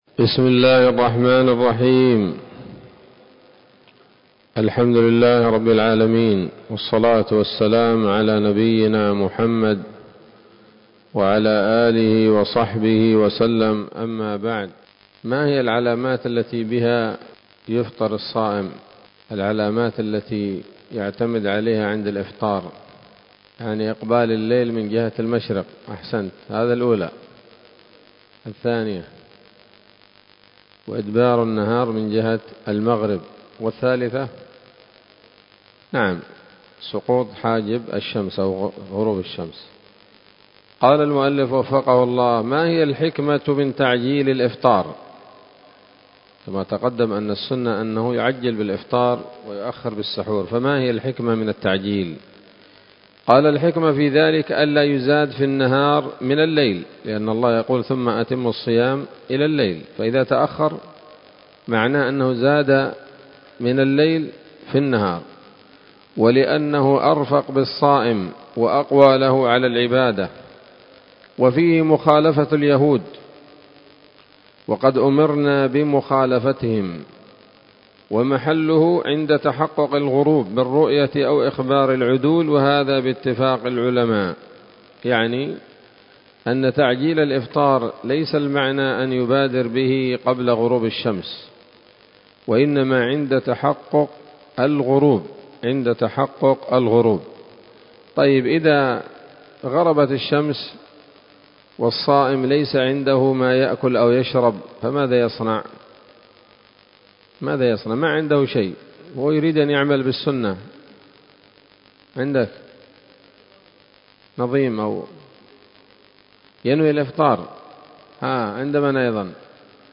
الدرس الثالث عشر من كتاب الصيام من نثر الأزهار في ترتيب وتهذيب واختصار نيل الأوطار